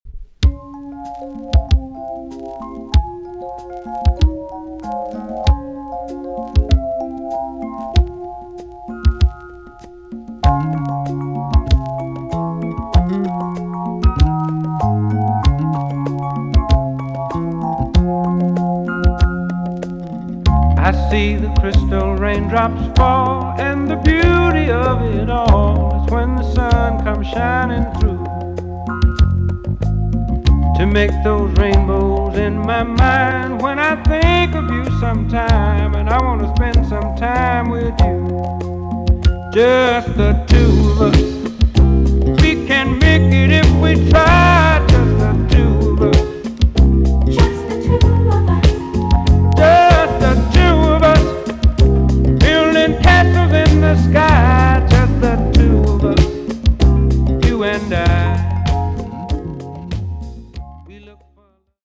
EX- 音はキレイです。